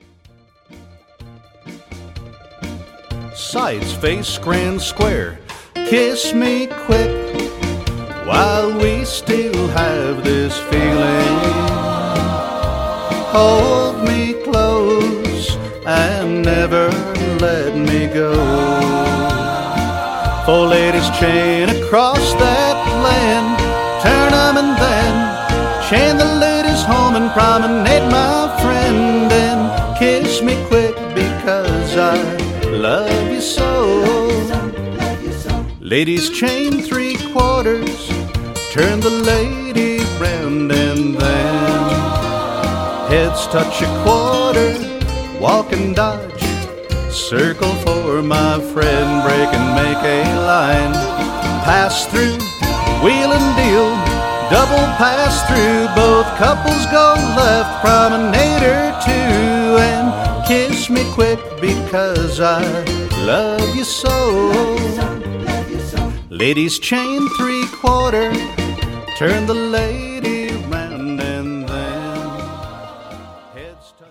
Category: Singing Calls